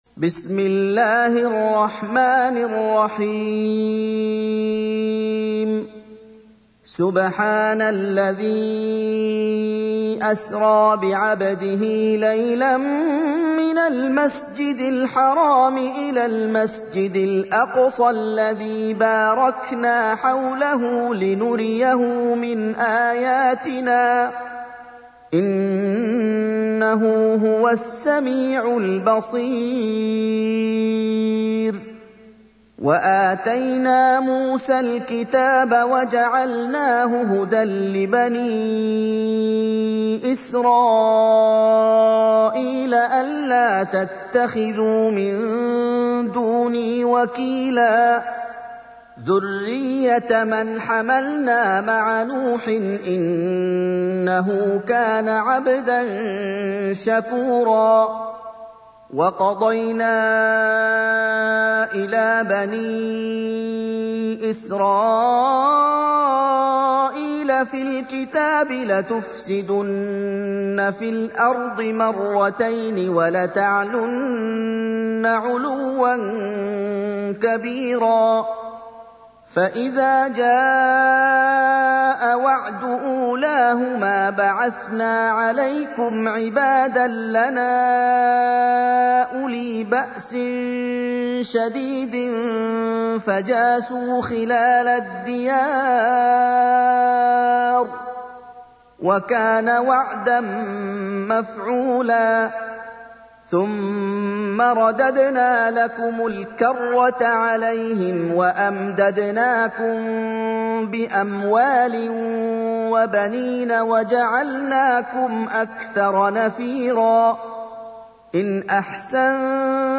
تحميل : 17. سورة الإسراء / القارئ محمد أيوب / القرآن الكريم / موقع يا حسين